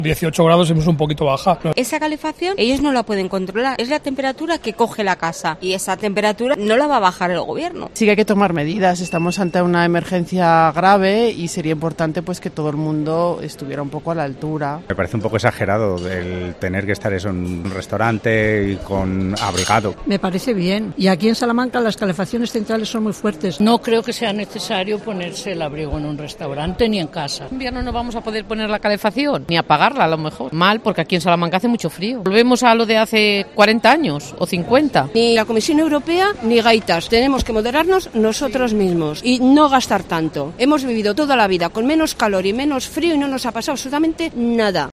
AUDIO: Los salmantinos opinan acerca de este Plan de ahorro y eficiencia energética